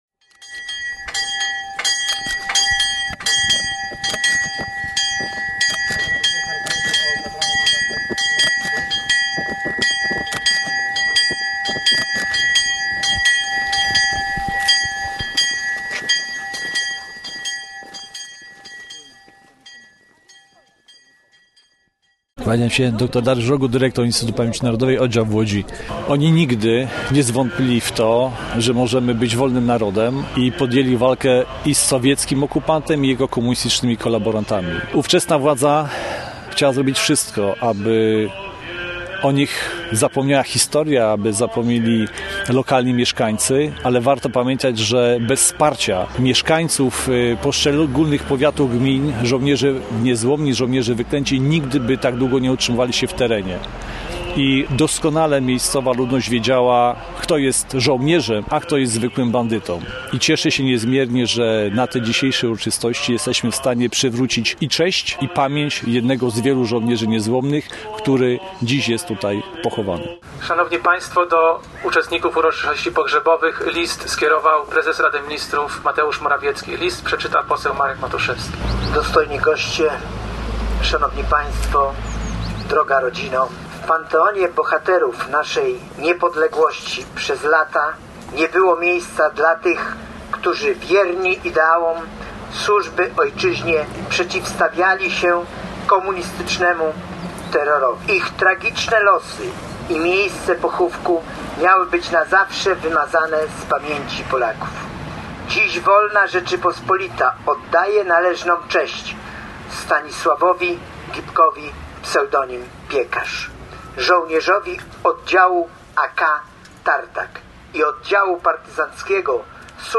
Wczoraj na wieluńskiej nekropolii odbyły się uroczystości pogrzebowe Stanisława Gibka, ps. „Piekarz”.
Dr Dariusz Rogut, dyrektor IPN Oddział w Łodzi: Oni nigdy nie zwątpili w to, że możemy być wolnym narodem i podjęli walkę z sowieckim okupantem i jego komunistycznymi kolaborantami.